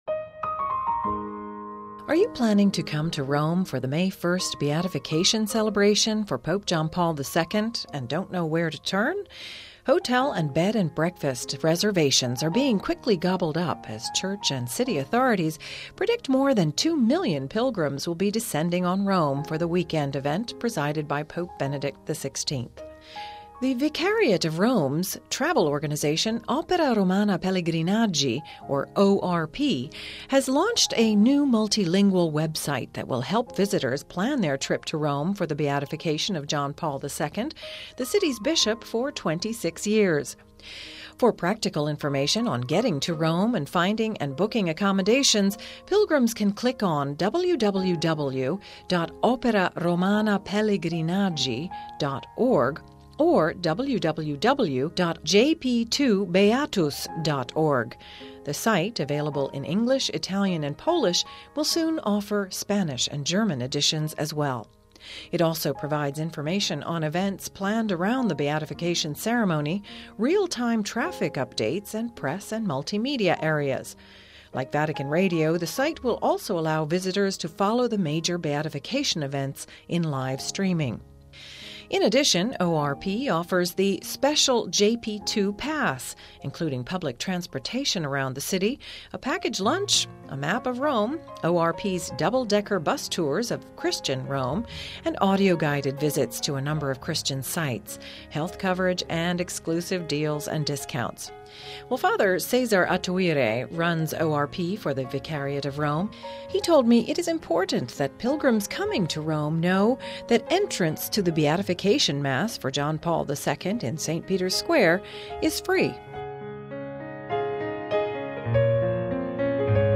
It’s never been done in the past and it will not be done on this occasion” Hear the full interview for more details about ORP’s travel tips: